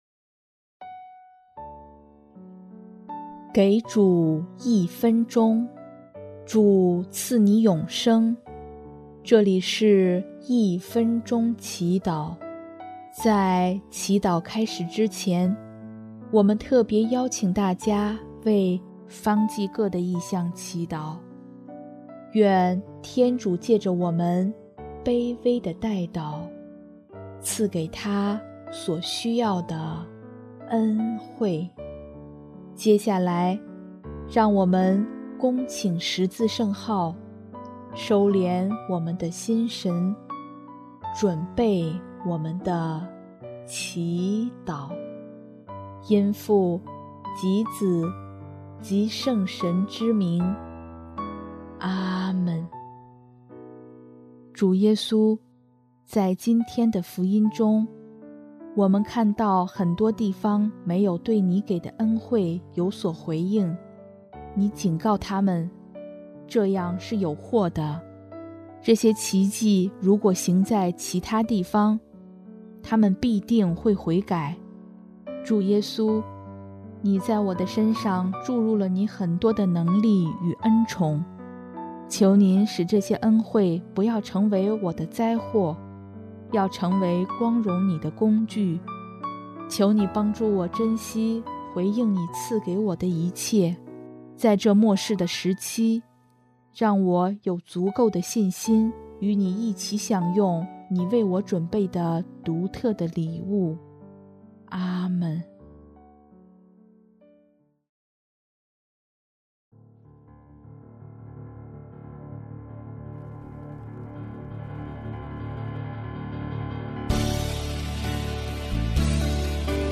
音乐： 第三届华语圣歌大赛参赛歌曲《相信》（方济各：求天主赐我力量，让我找回状态，积极生活，远离痛苦的思想）